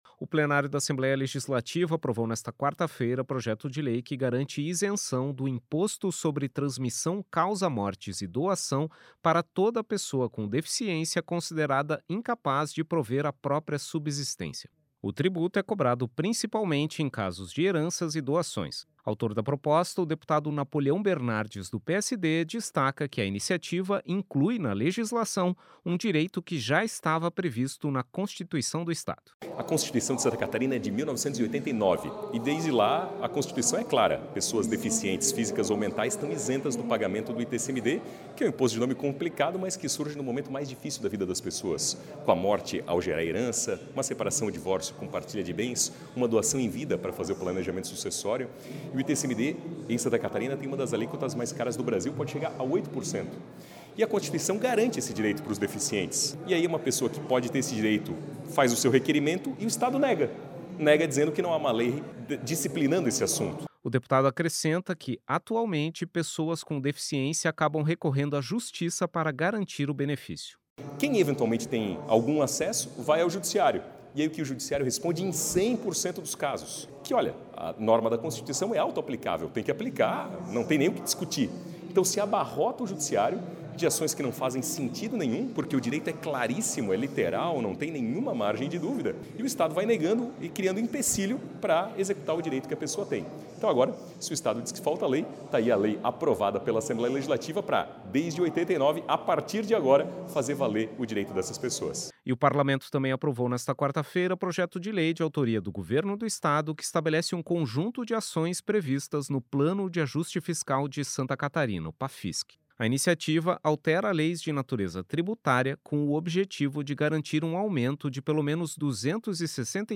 Entrevista com:
- deputado Napoleão Bernardes (PSD), autor do projeto de lei que garante isenção do ITCMD para pessoas com deficiência.